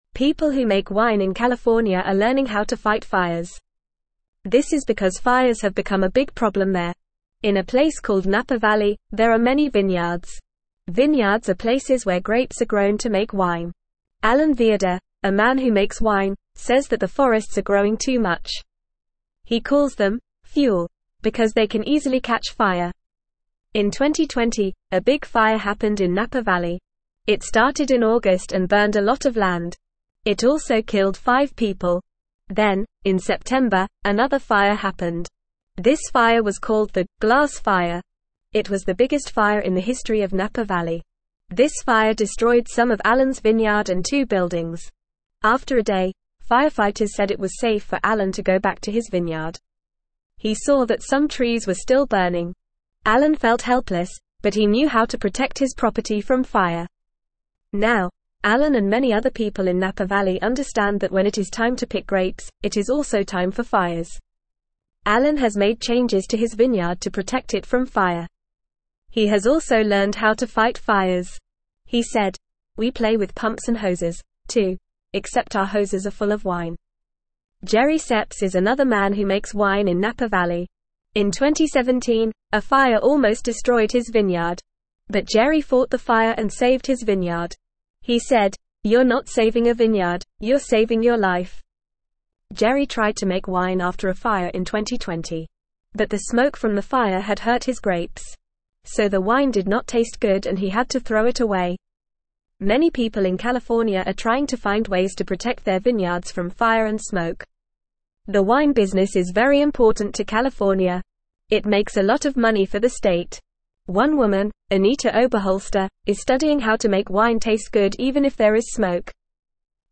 Fast
English-Newsroom-Beginner-FAST-Reading-California-Winemakers-Learn-to-Fight-Fires.mp3